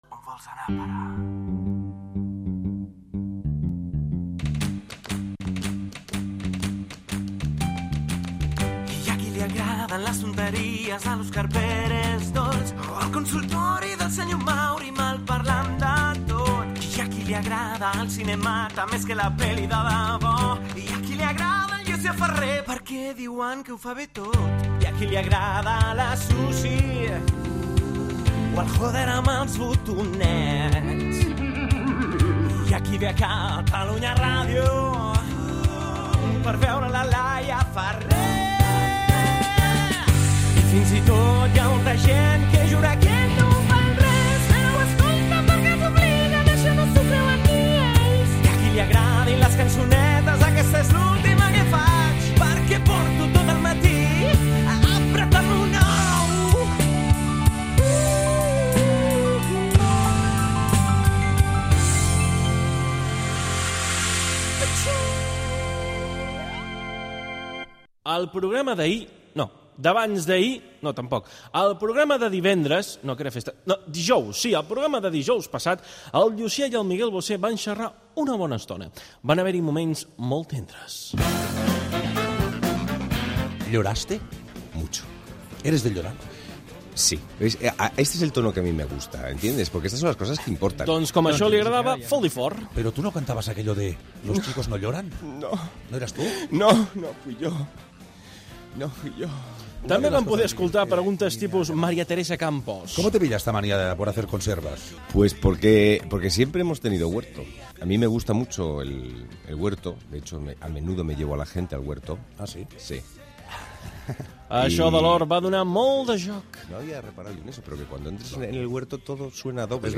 Indicatiu del programa, cançó del programa, resum dels programes de la setmana anterior (en un es va entrevistar al cantant Miguel Bosé), indicatiu del programa, presentació amb els noms de l'equip, el preu del cafè als bars
Entreteniment